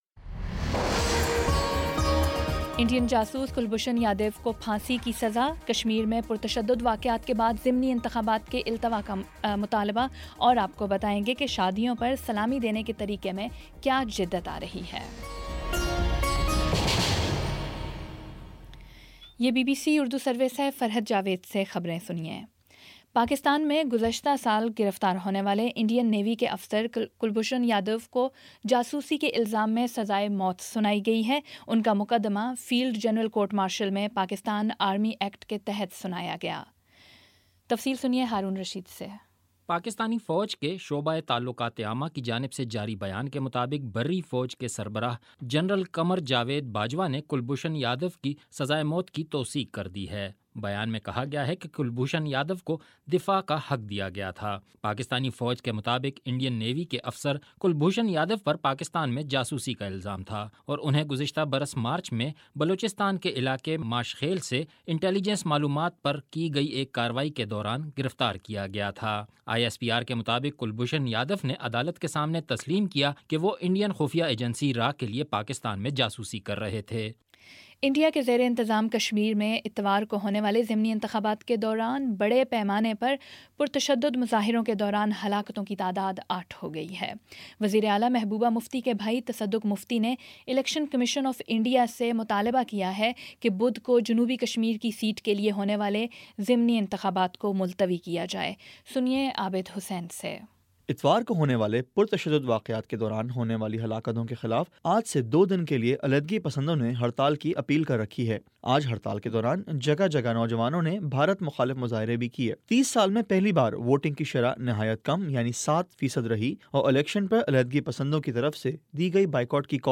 اپریل 10 : شام پانچ بجے کا نیوز بُلیٹن